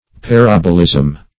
Search Result for " parabolism" : The Collaborative International Dictionary of English v.0.48: Parabolism \Pa*rab"o*lism\ (p[.a]*r[a^]b"[-o]*l[i^]z'm), n. [From Parabola .]
parabolism.mp3